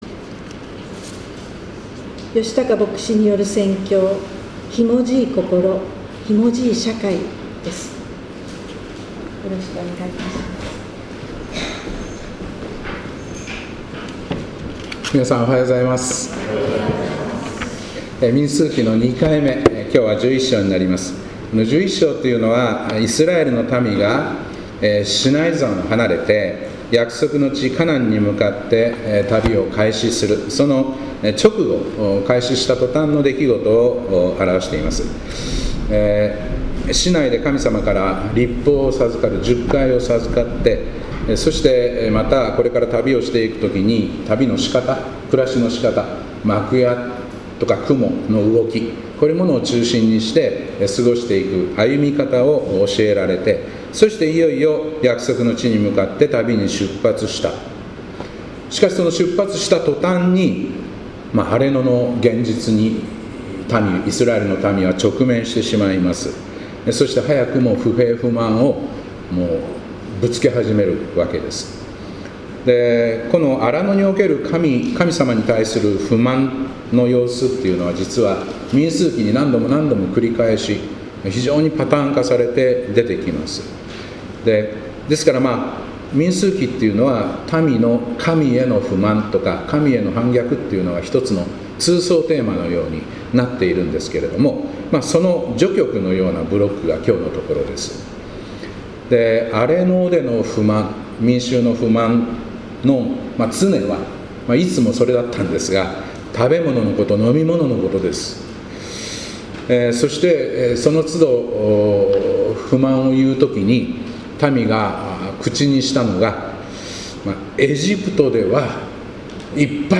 2025年7月13日礼拝「ひもじい心、ひもじい社会」